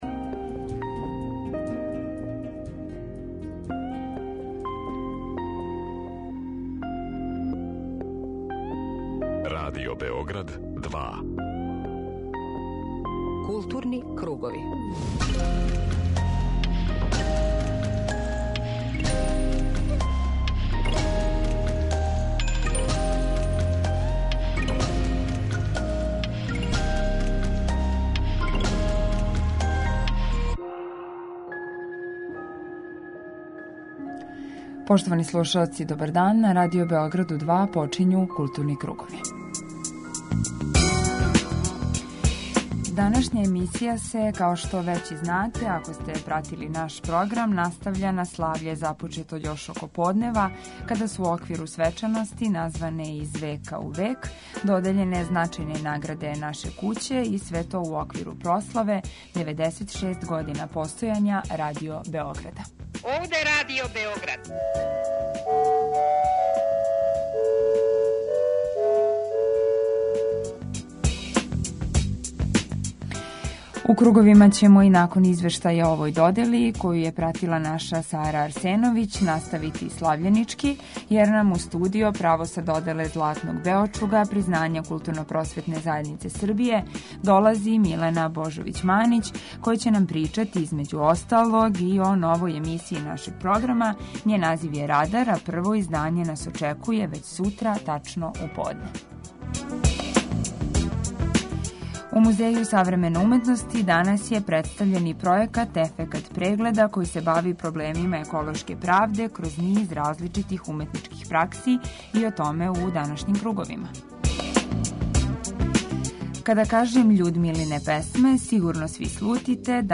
Деведест шест година постојања Радио Београда - пратимо свечаност из Камене сале